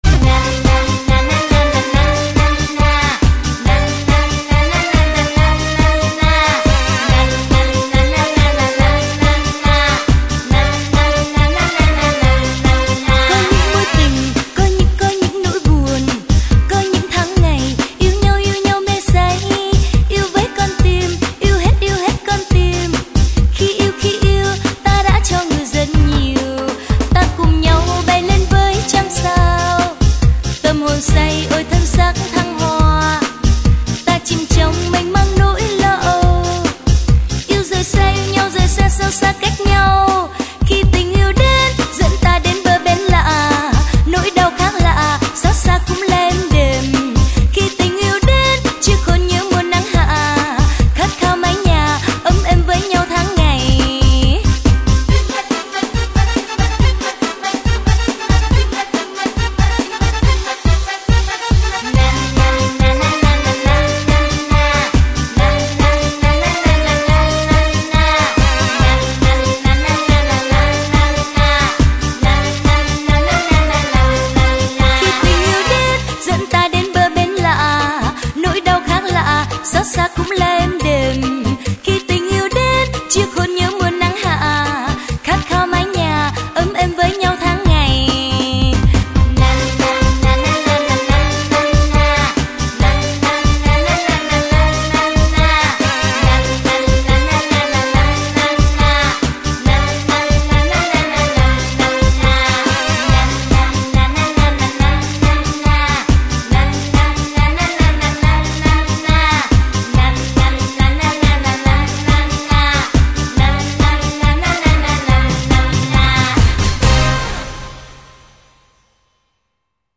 Veillée karaoké Le soir, tout le village nous rejoint pour une fête organisée par la jeunesse communiste.
Cliquez ici pour entendre le tube de l'été vietnamien, sur lequel nous avons dansé comme des petits fous !